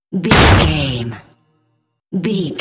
Dramatic hit wood bloody
Sound Effects
heavy
intense
dark
aggressive
hits